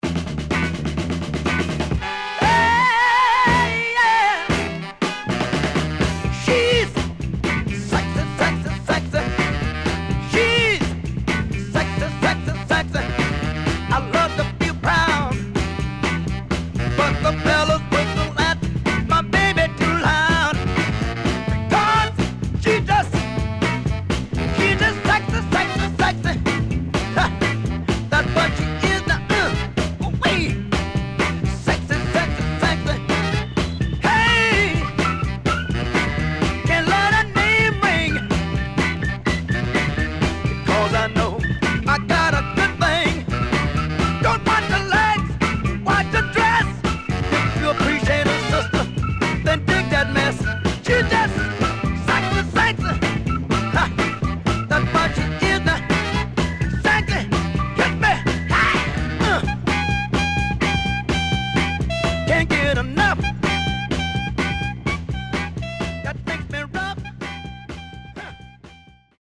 CONDITION SIDE A:VG(OK)
SIDE A:所々チリノイズがあり、少しプチノイズ入ります。